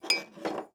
SFX_Glass_01.wav